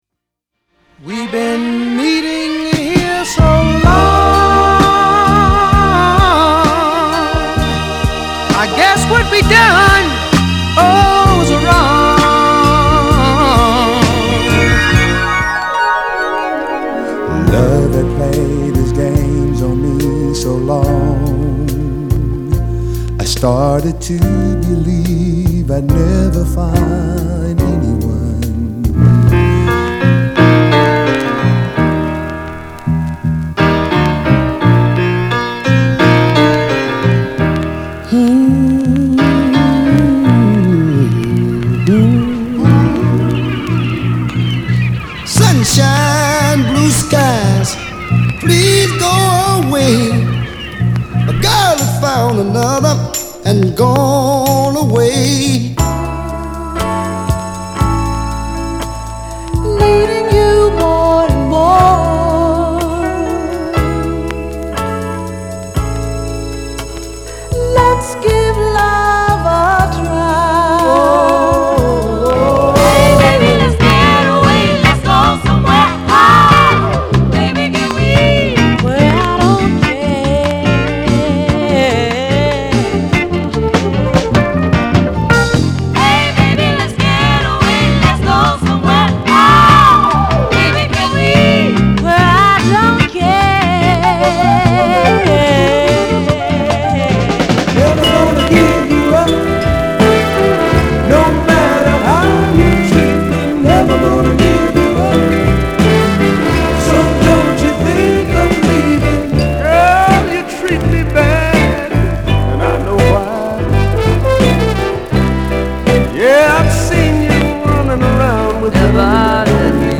/盤質/両面やや傷あり再生良好/US PRESS